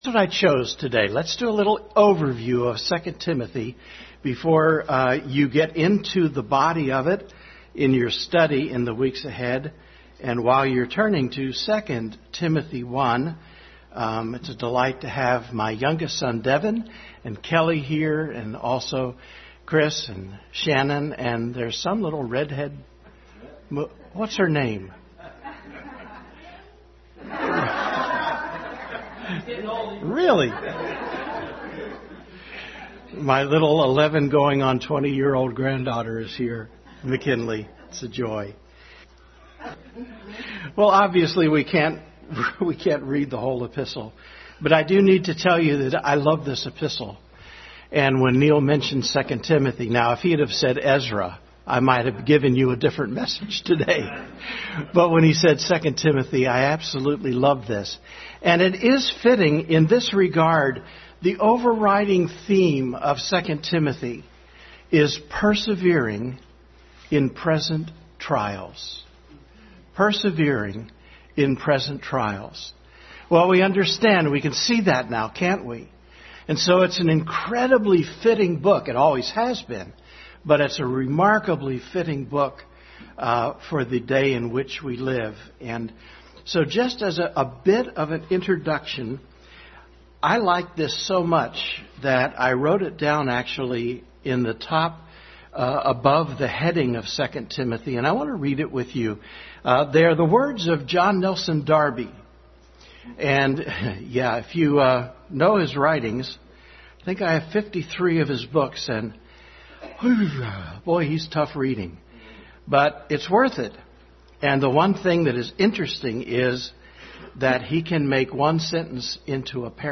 2 Timothy Overview Passage: Various Scriptures from 2 Timothy Service Type: Family Bible Hour